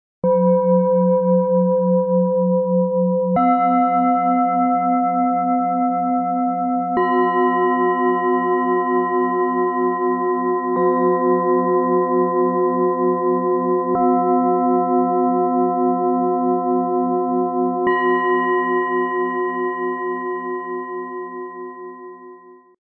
Spielerisch wachsen - Fließende Balance und Klarheit für Meditation und Gruppen - Set aus 3 Planetenschalen, Ø 15,2 -18,2 cm, 2,27 kg
Im Sound-Player - Jetzt reinhören können Sie den Original-Ton genau dieser Schalen aus dem Set anhören.
Tiefster Ton: Wasser
Region: UniversalMittlerer Ton: Delfin
Region: Solarplexus – HerzHöchster Ton: Jupiter
MaterialBronze